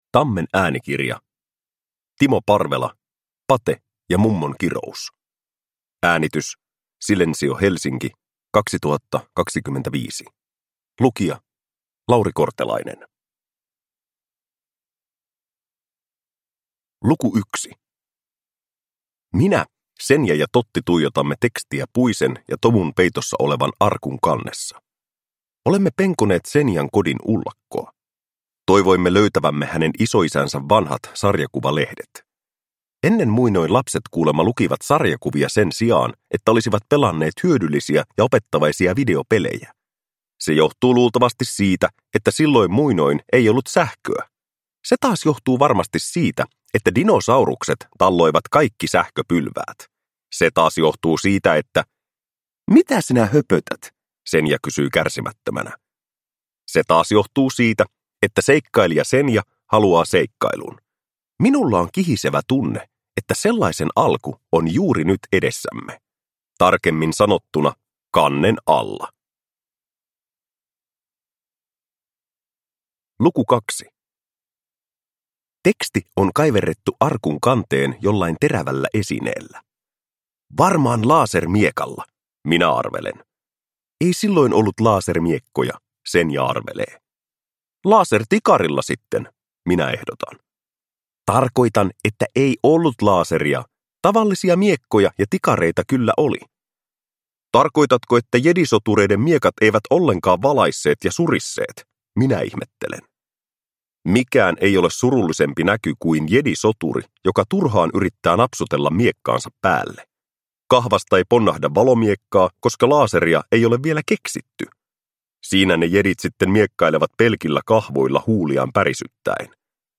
Pate ja mummon kirous – Ljudbok